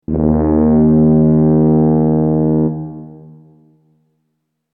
blast every 120 seconds!
We were talking pea soup – foghorn thick – bbbrrrrrrrr!
fog-horn-sound.mp3